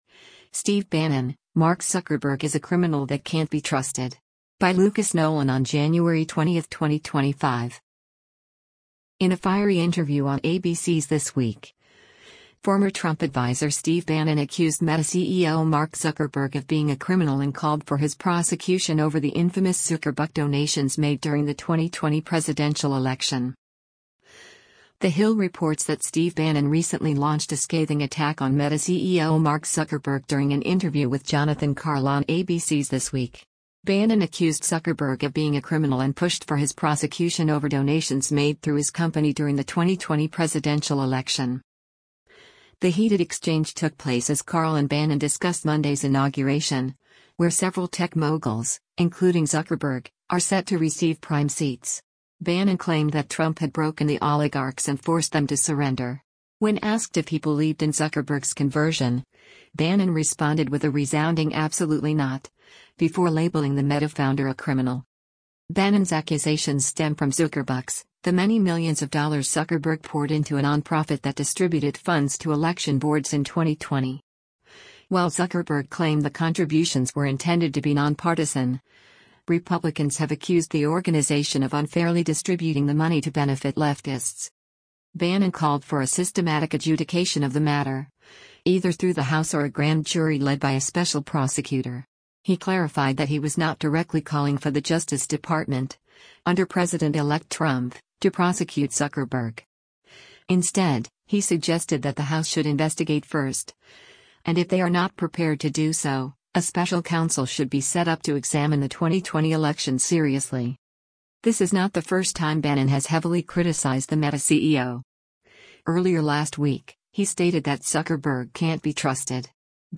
In a fiery interview on ABC’s This Week, former Trump adviser Steve Bannon accused Meta CEO Mark Zuckerberg of being a criminal and called for his prosecution over the infamous “Zuckerbuck” donations made during the 2020 presidential election.